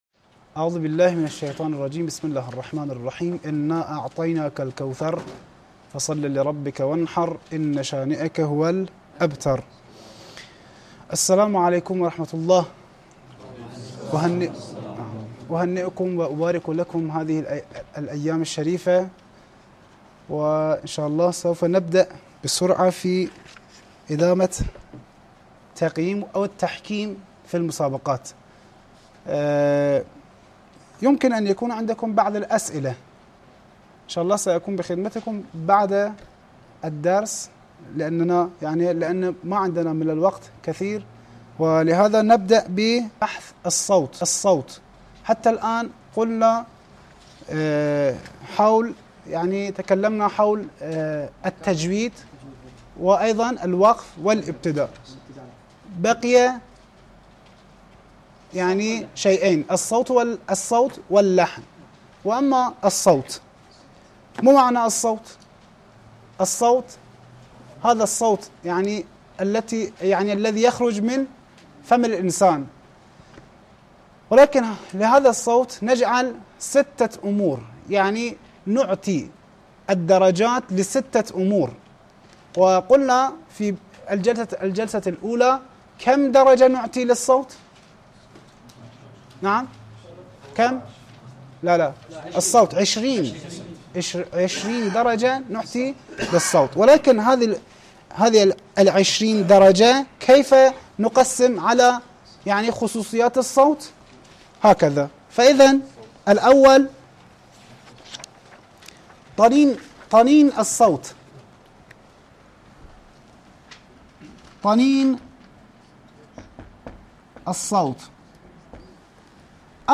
الدرس الثالث - لحفظ الملف في مجلد خاص اضغط بالزر الأيمن هنا ثم اختر (حفظ الهدف باسم - Save Target As) واختر المكان المناسب